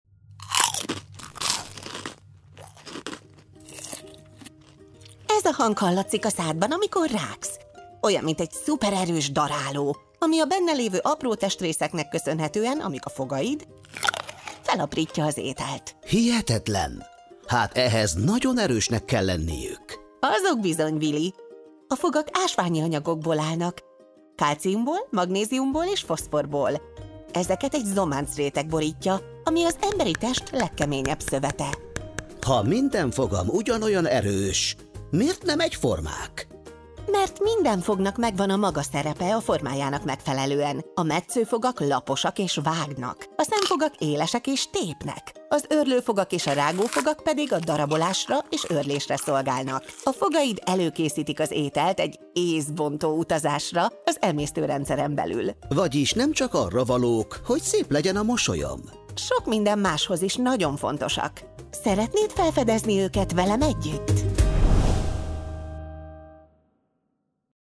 A sorozat II. lapszámához tartozó játék sztetoszkóppal meghallgatható bizonyos testrészek hangja, valamint lejátszható egy rövid párbeszéd a testrésszel kapcsolatos érdekességekről.